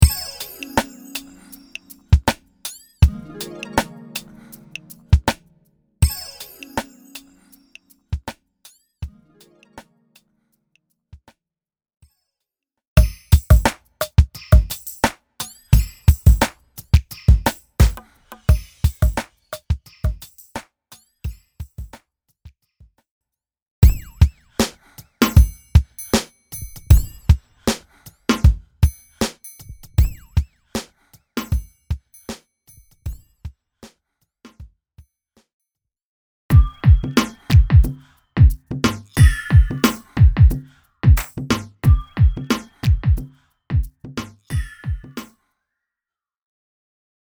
A collection of 38 sensual sounds for perfect sexy beats.
This kit contains various drum, percussive and SFX elements, professionally recorded and massaged to give you instant gratification.
This is a construction kit - a collection of high quality one shots for you to play or program with your drum sampler.
sensualdemo.mp3